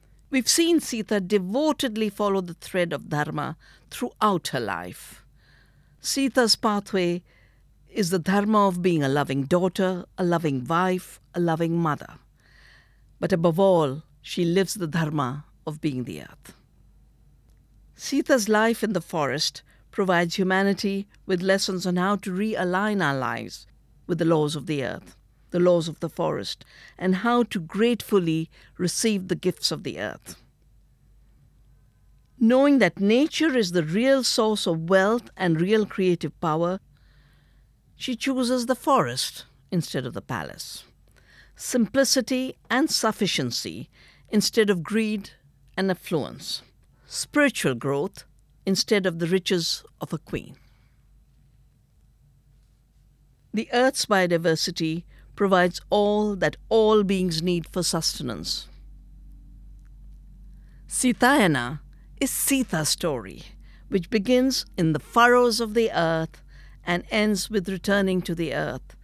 • Forward and conclusion by Vandana Shiva
Vandana Shiva–Conclusion (excerpt)